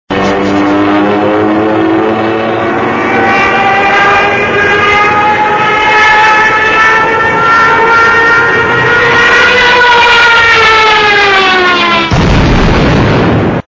Падающий самолёт (0:13 32 kbit 53,4 КБ)